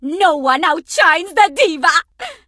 diva_kill_vo_03.ogg